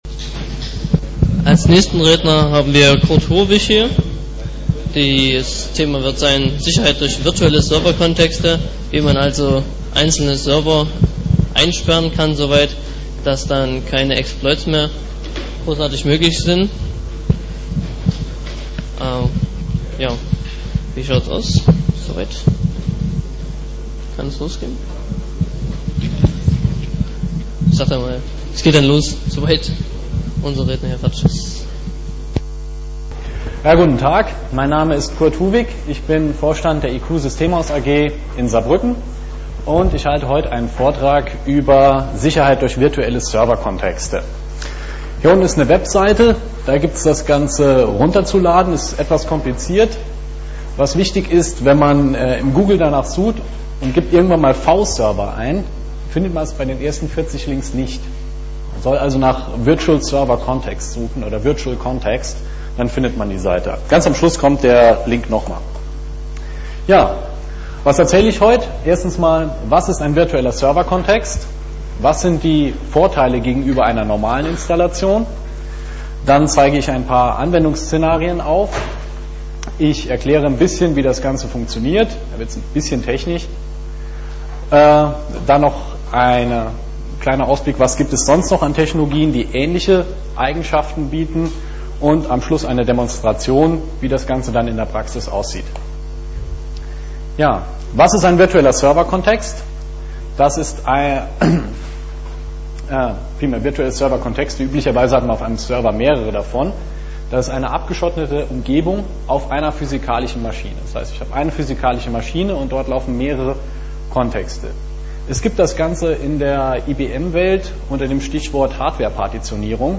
Material zum Vortrag (i.d.R. die Vortragsfolien) 16kbps Vortragsmittschnitt als MP3 (6.36 Mbyte) 32kbps Vortragsmittschnitt als MP3 (12.73 Mbyte)